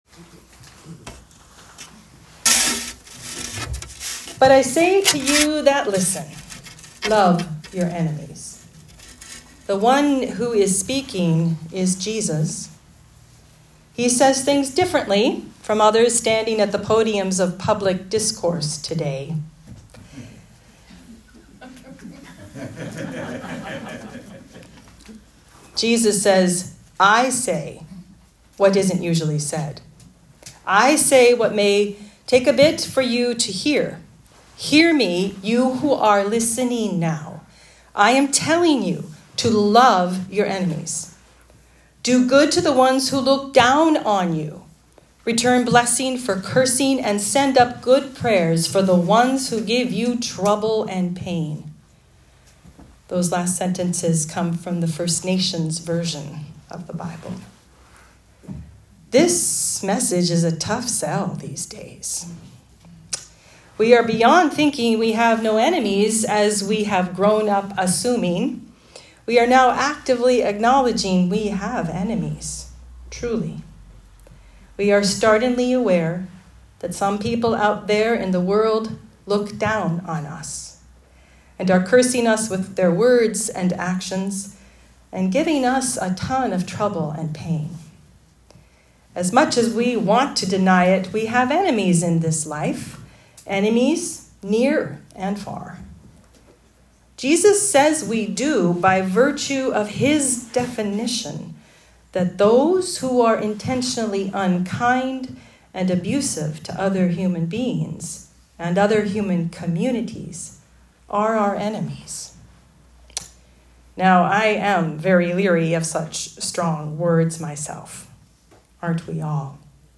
Sermons | Holy Trinity North Saanich Anglican Church